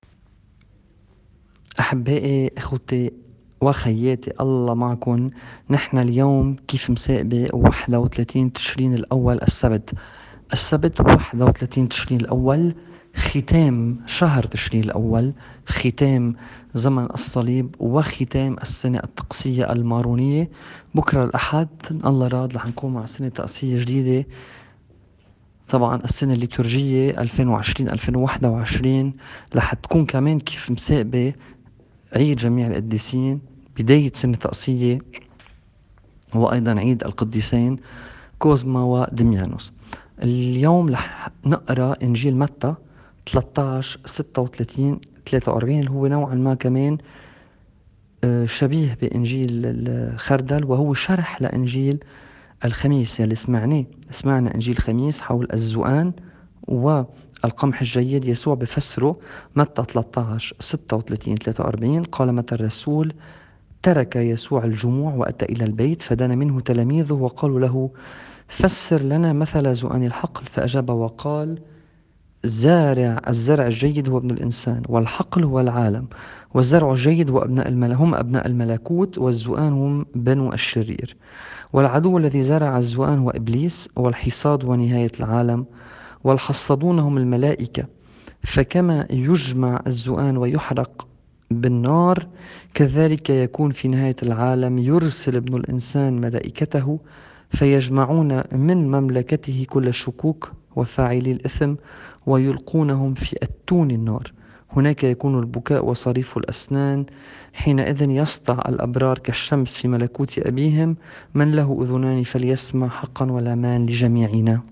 الإنجيل بحسب التقويم الماروني :